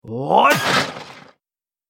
دانلود آهنگ دعوا 15 از افکت صوتی انسان و موجودات زنده
دانلود صدای دعوای 15 از ساعد نیوز با لینک مستقیم و کیفیت بالا
جلوه های صوتی